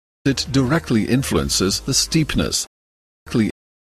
Likewise perfectly, exactly and directly can sound like perfeckly, exackly and direckly:
(Notice how the last speaker is unusually careful about exploding the t in it and the p in steepness, but not the t in directly.)